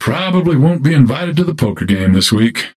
Dynamo voice line